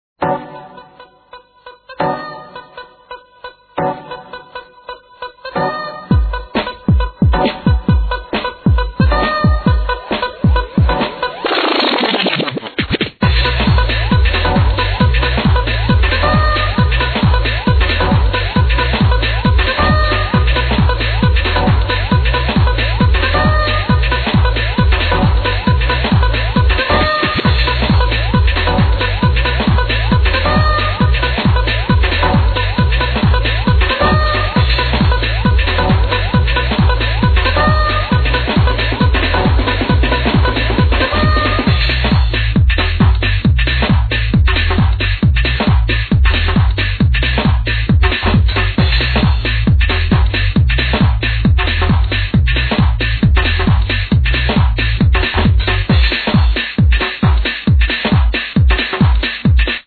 Plz help me id these old techno tracks